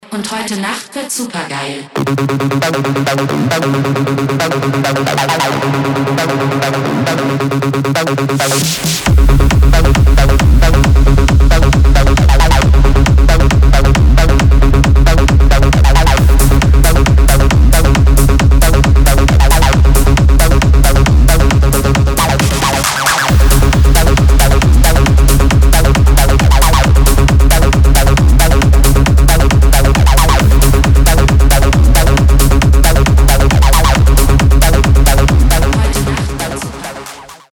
клубные
edm , жесткие , техно
мощные